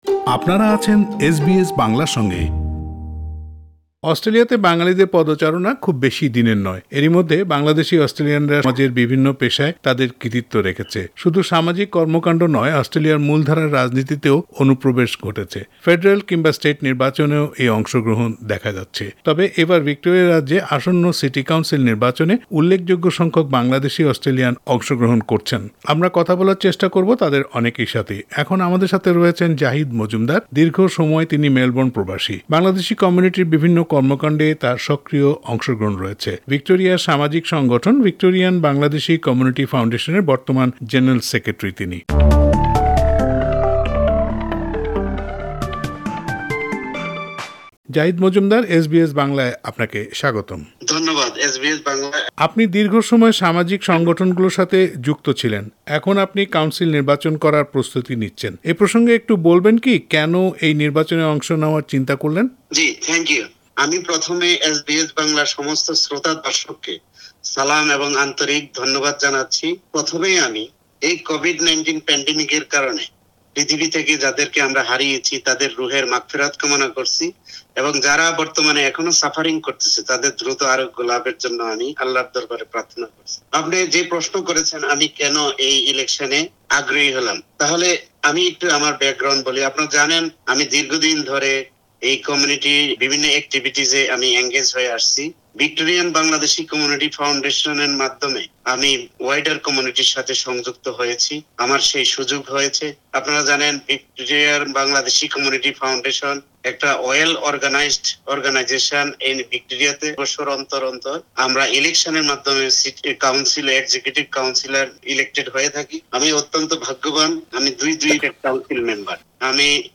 এই নির্বাচন নিয়ে এসবিএস বাংলার সাথে কথা বলেছেন